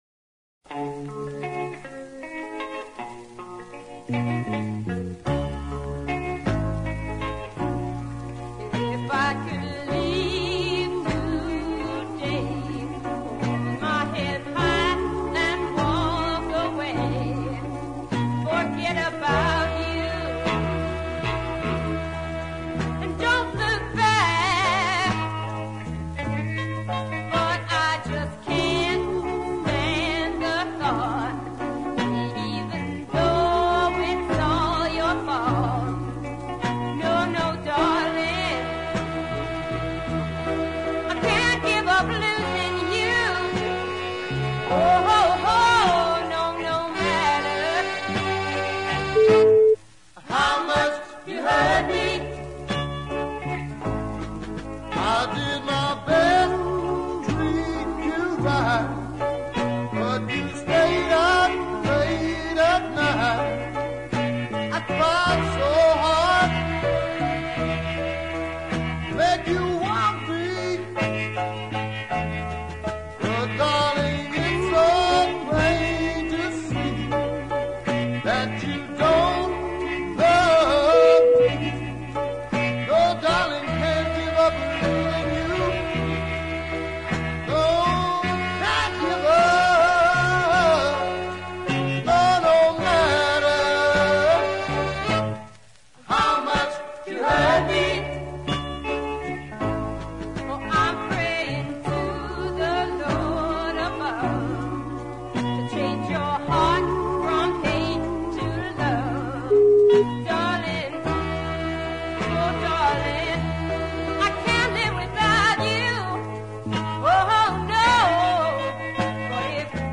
Male/female duo
high quality deep